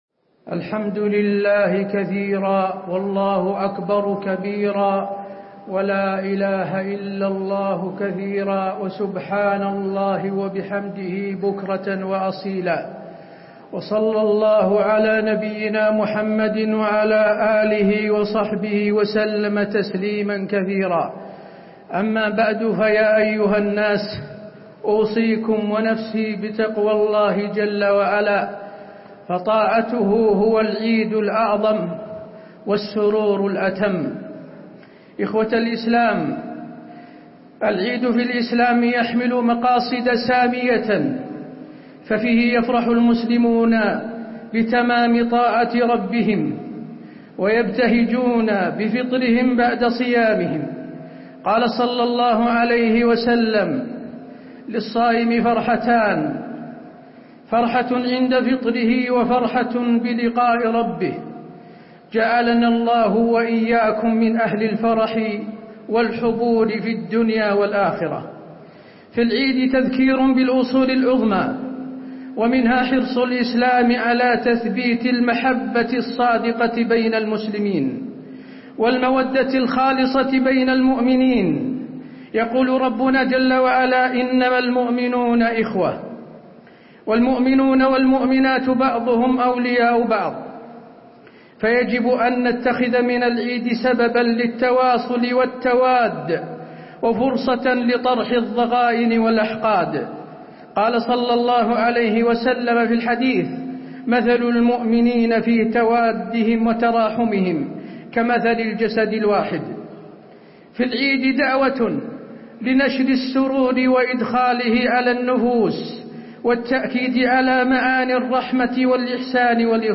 خطبة عيد الفطر- المدينة - الشيخ حسين آل الشيخ 1-10-
المكان: المسجد النبوي